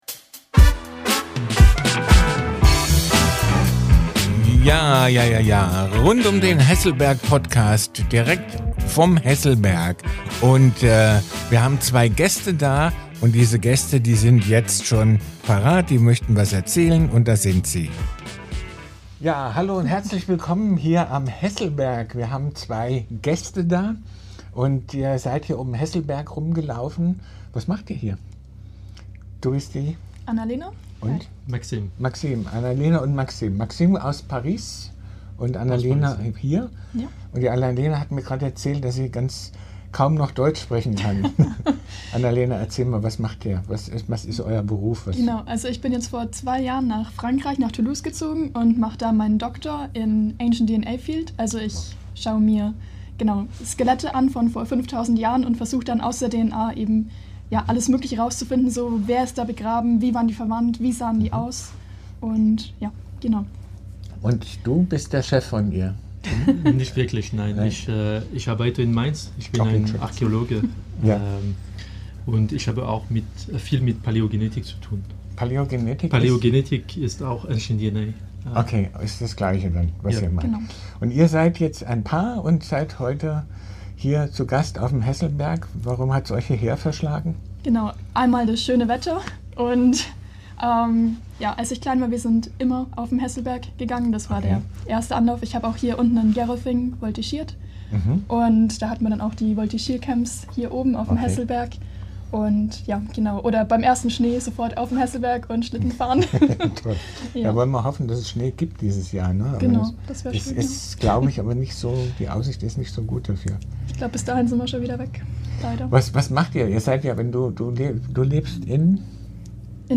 Zwei junge Forscher berichten von DNA, Liebe auf Distanz und besonderen Funden. Ein Gespräch zwischen Wissenschaft und Heimatgefühl.